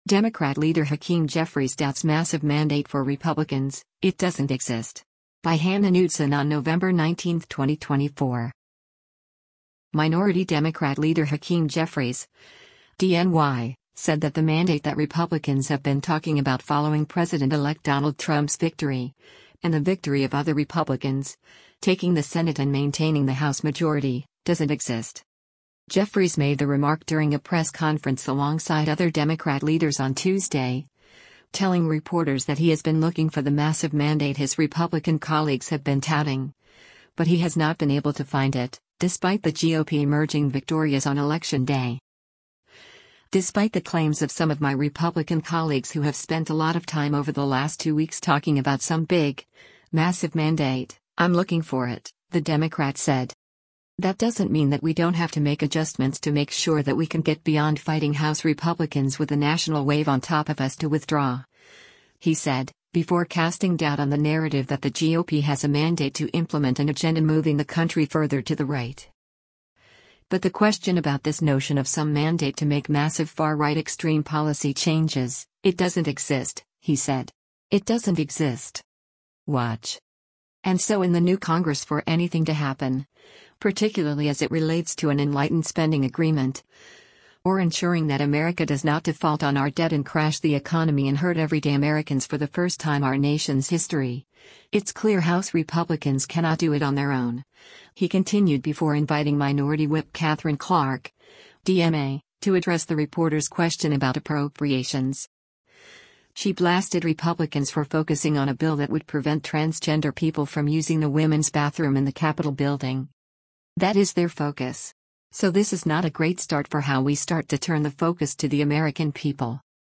Jeffries made the remark during a press conference alongside other Democrat leaders on Tuesday, telling reporters that he has been looking for the “massive mandate” his Republican colleagues have been touting, but he has not been able to find it, despite the GOP emerging victorious on Election Day.